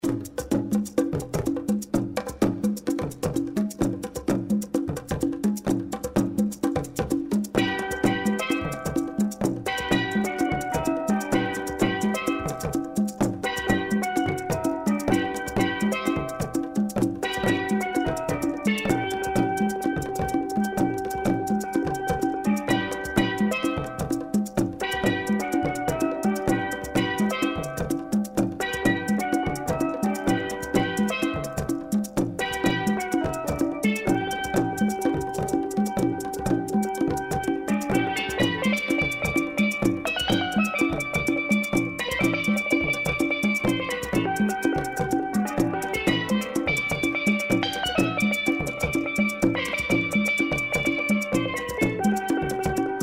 Steel pan and kora
"Mesmerising steel pan"